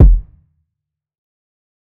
TC2 Kicks18.wav